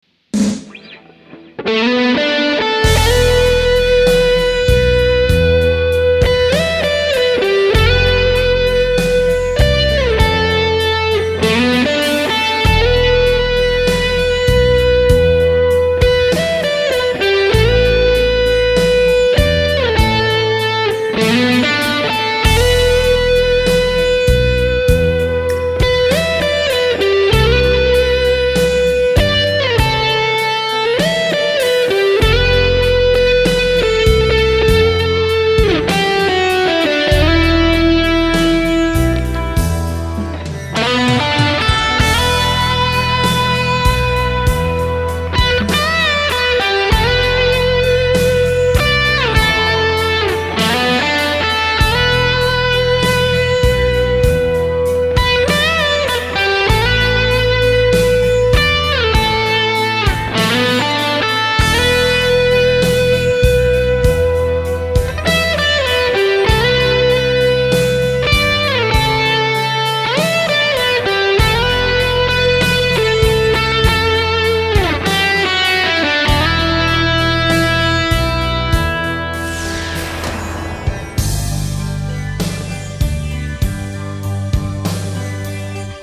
Китара -> Lee Jackson GP1000 Tube Preamp ->Ultra-G (Speaker Simulation ON) -> Mixer -> sound card
Ламповият преамп е роден през 80-те години и затова има и такъв звук - няма особено много гейн, но мен страшно си ме кефи и дори намирам звука за плътен.